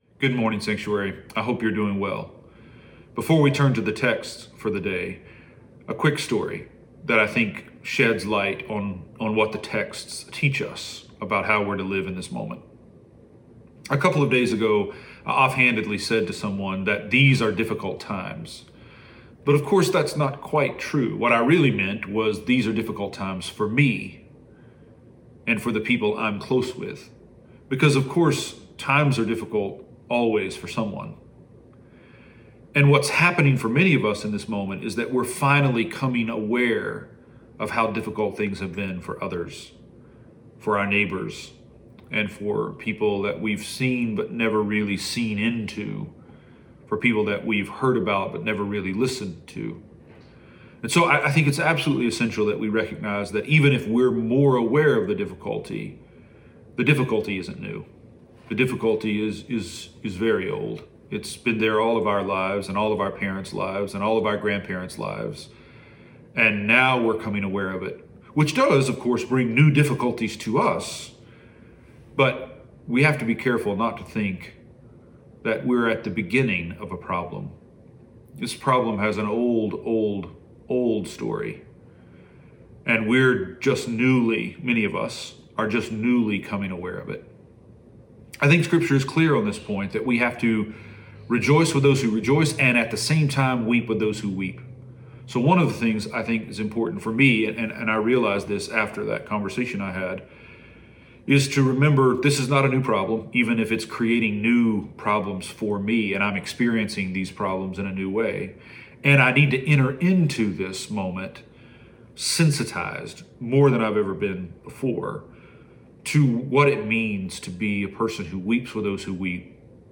Weekly sermon podcasts from Sanctuary Church in Tulsa, OK